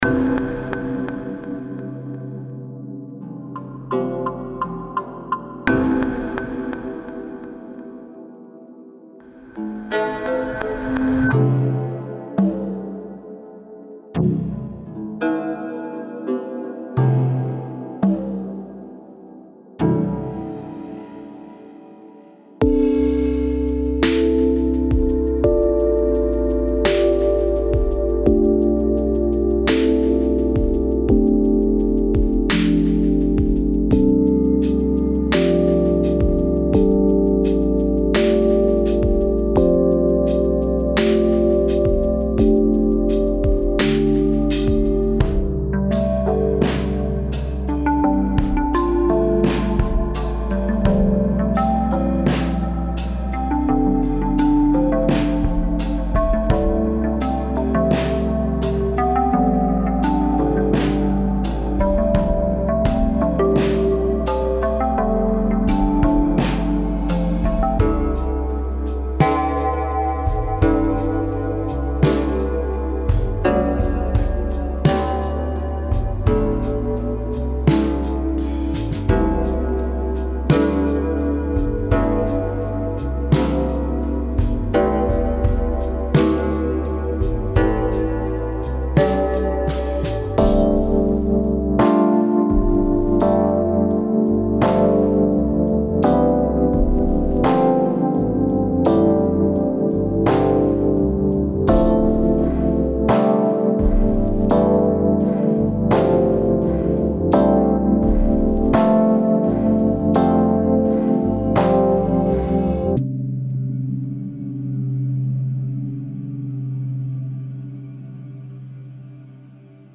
深入了解催眠蒸气波的声音和循环！
如果您正在寻找具有许多可操纵频率的独特的lo-fi声音集合，以使其听起来像被录制在黑胶唱片或磁带上，则别无所求。